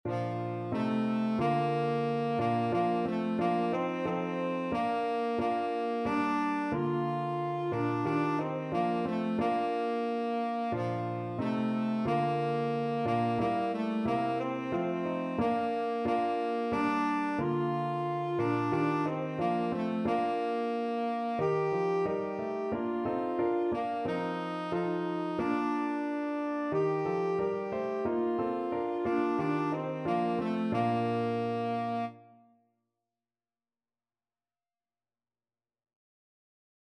Christmas